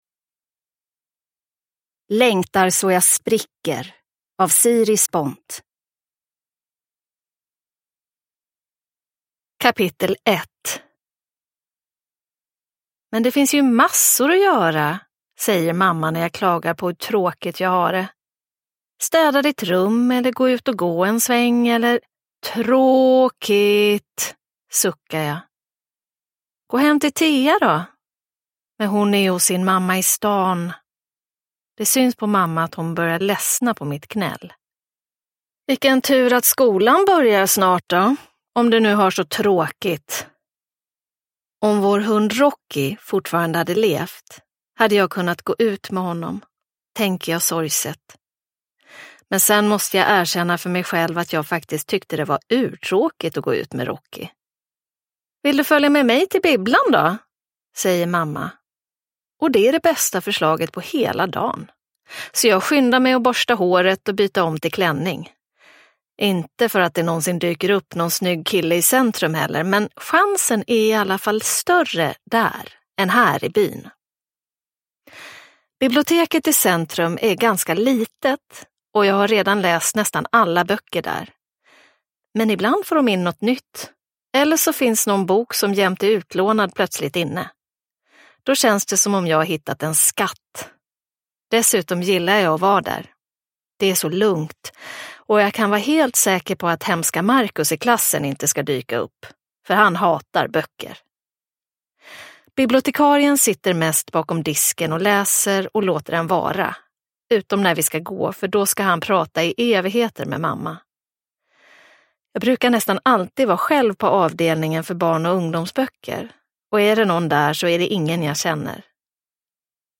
Längtar så jag spricker – Ljudbok – Laddas ner